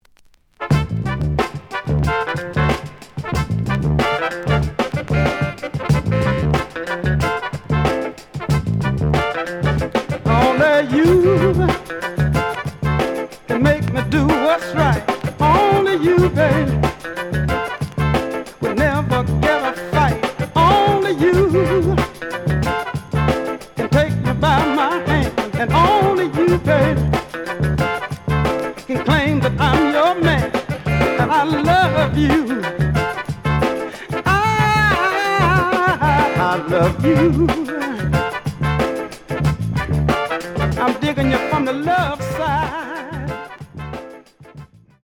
The audio sample is recorded from the actual item.
●Genre: Funk, 70's Funk
Slight damage on both side labels. Plays good.)